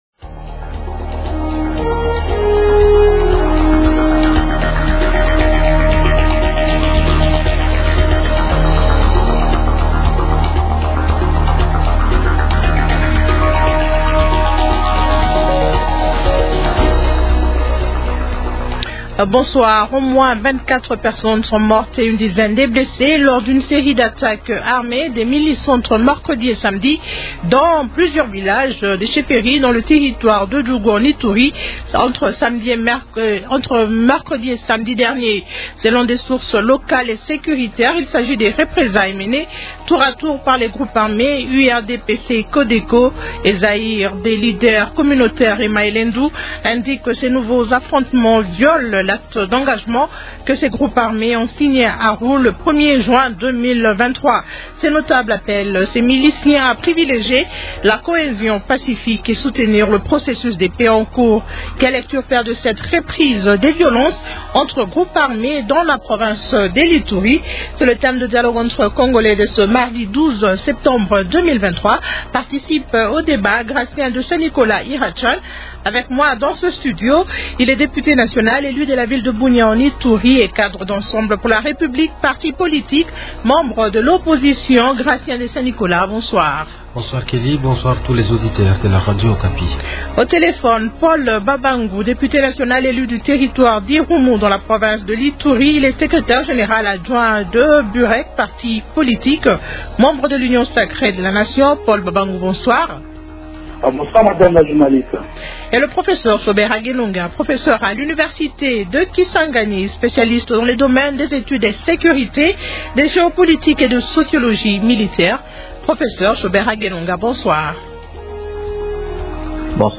Question : -Quelle lecture faire de cette reprise des violences entre groupes armés dans la province de l’Ituri ? Invités : -Gratien de Saint-Nicolas Iracan, député national élu de la ville de Bunia (Ituri).
-Paul Babangu, député national élu du territoire d’Irumu, dans la province de l’Ituri.